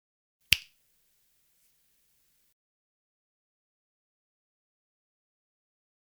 フィンガースナップ
こちらはフィンガースナップといっていわゆる指パッチンの音ですね。
これに関してはボクが自分で録音しました。笑